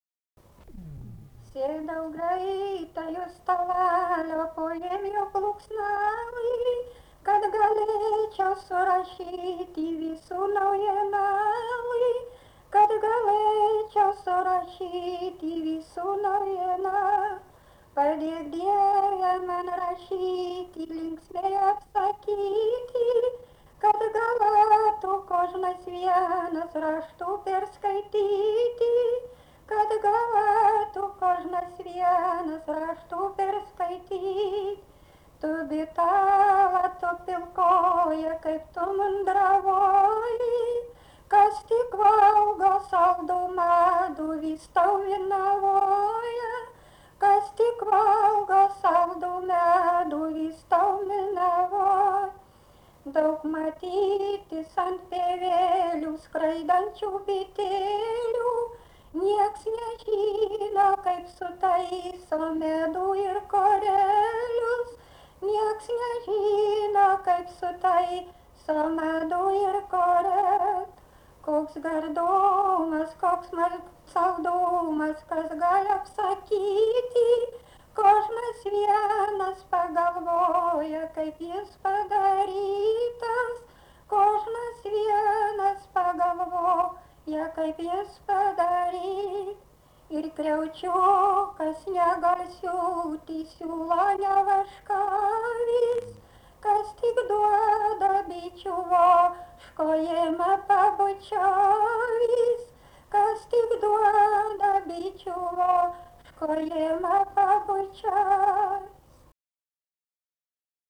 daina, kalendorinių apeigų ir darbo
Erdvinė aprėptis Suvainiai
Atlikimo pubūdis vokalinis